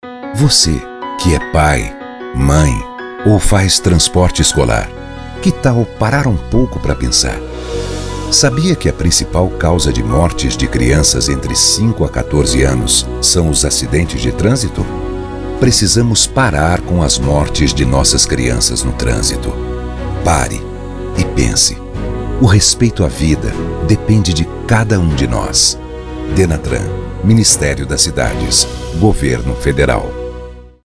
Spots de Rádio